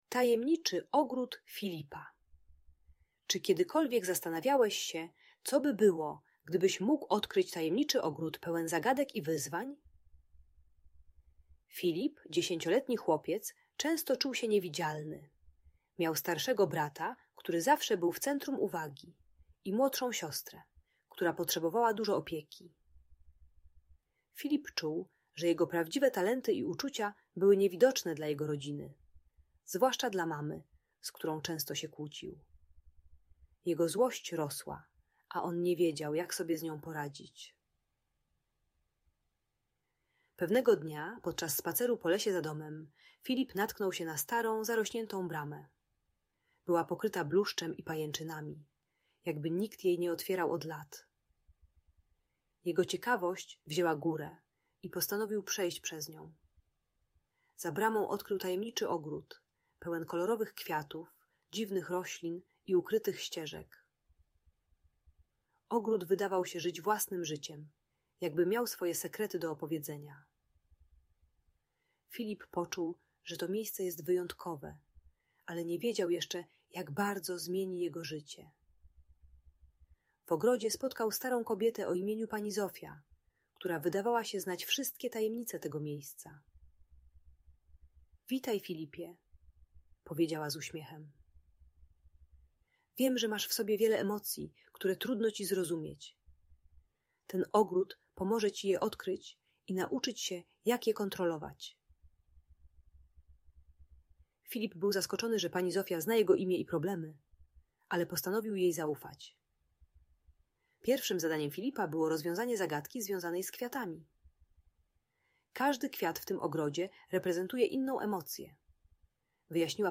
Tajemniczy ogród Filipa - magiczna story pełna emocji - Audiobajka